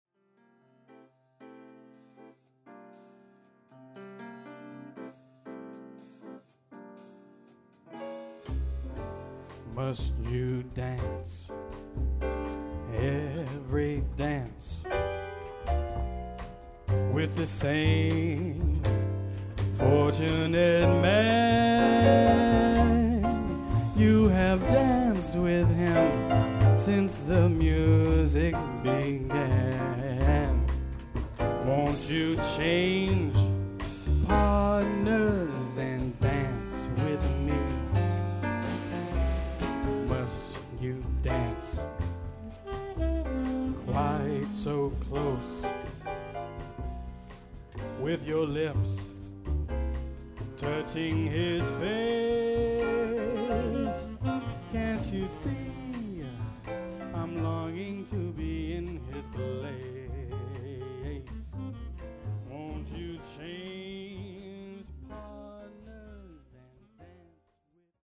A throw back to Jazz Crooning!